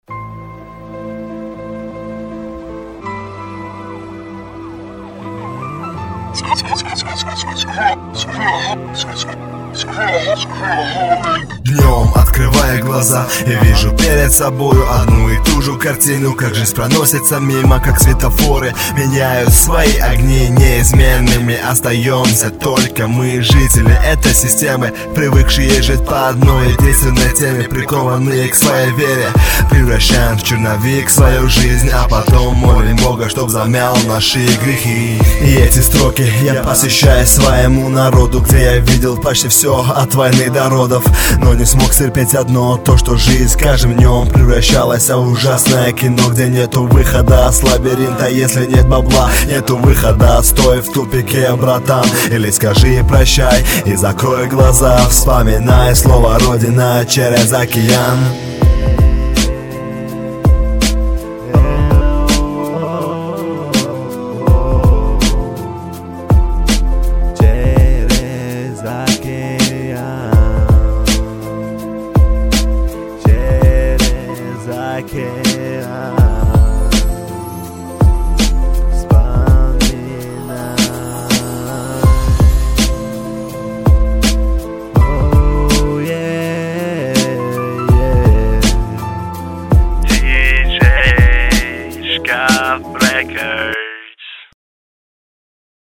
Главная » Каталог mp3 » Рэп / HIP HOP » Tajik Rap
автотюн убил biggrin